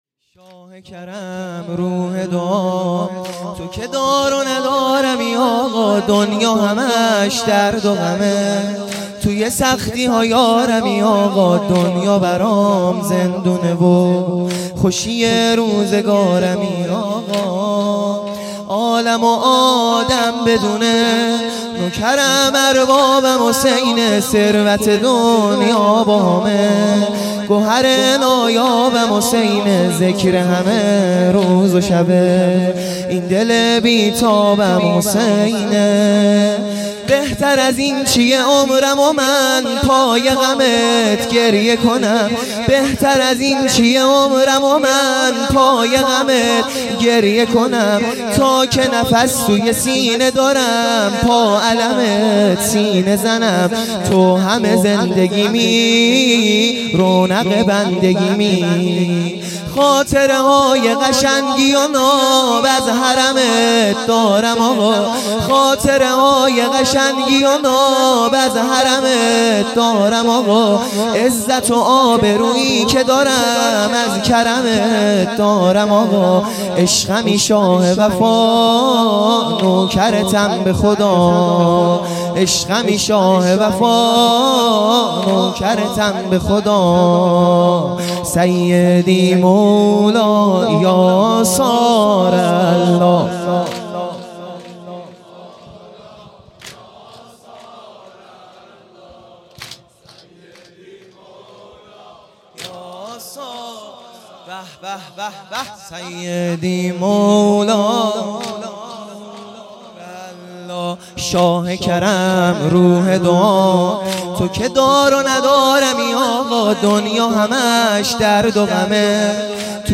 دهه اول صفر | شب دوم